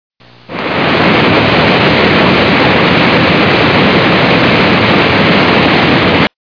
Water Falls
Tags: nature sounds raven ocean wind thunder/lightening